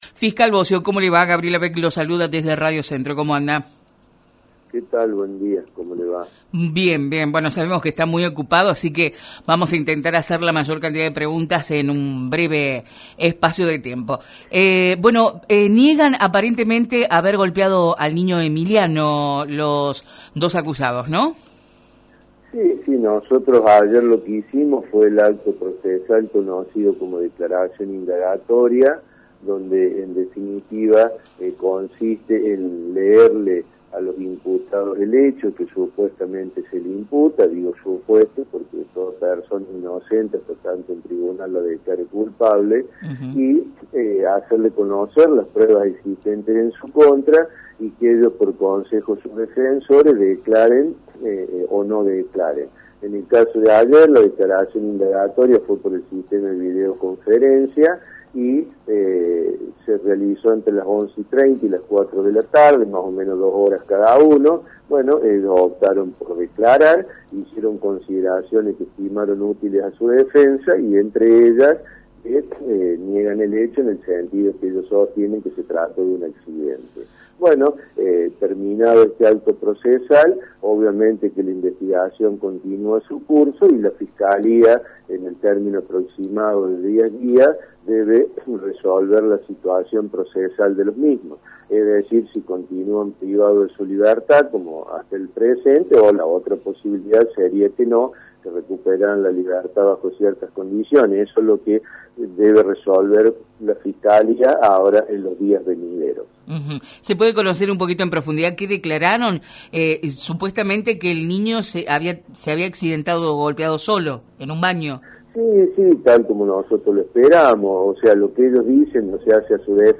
Este miércoles, el Fiscal René Bosio se refirió al caso en «La Mañana Informal» de Radio Centro y nos indicó que ayer efectivamente se tomó la declaración de indagatoria y que fue por videoconferencia durante dos horas cada uno ya que están detenidos desde hace poco más en la cárcel de Bouwer.